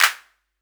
Tr8 Clap 01.wav